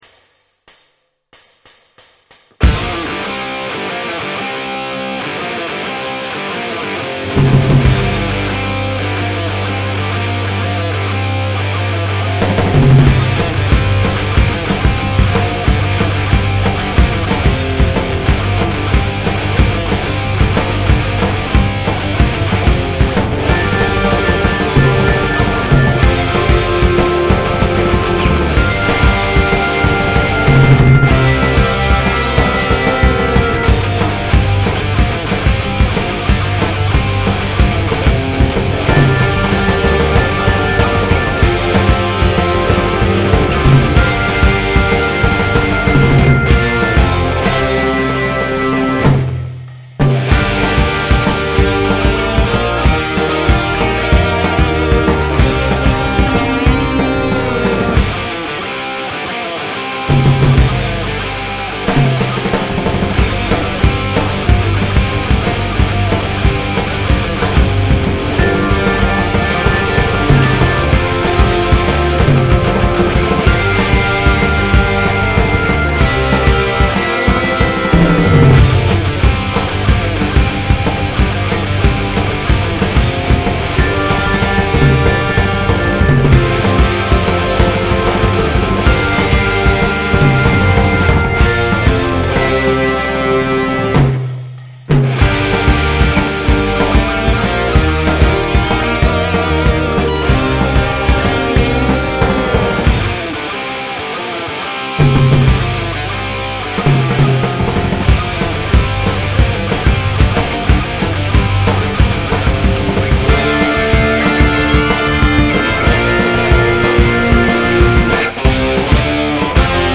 2012-3年頃の北京録音、Marriottテープから。